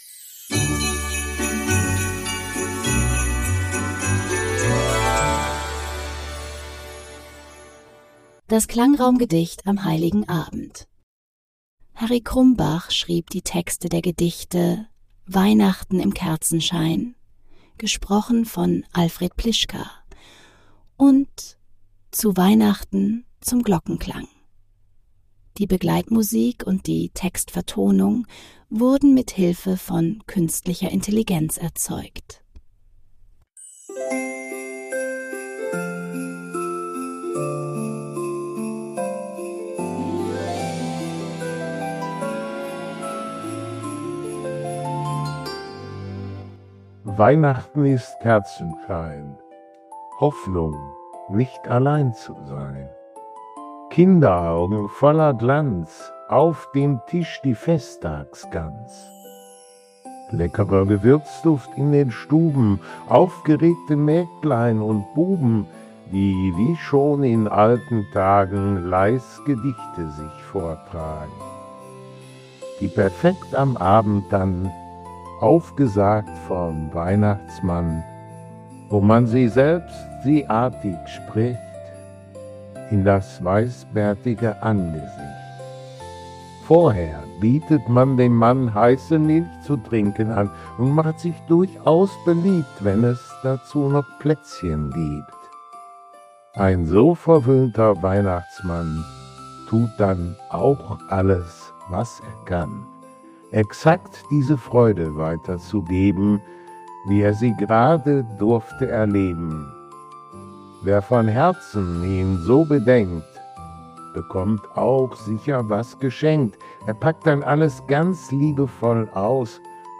Die Begleitmusik und die Textvertonung wurden